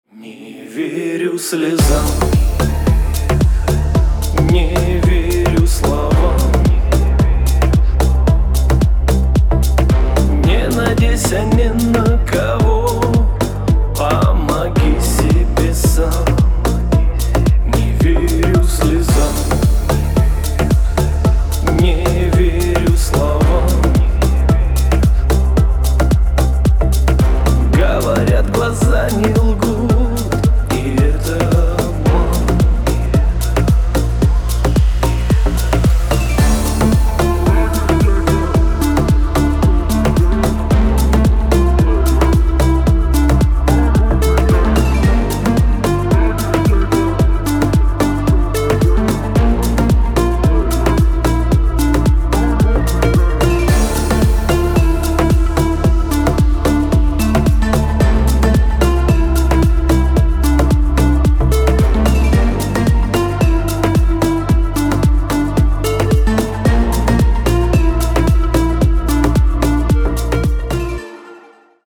мужской вокал
deep house
электронная музыка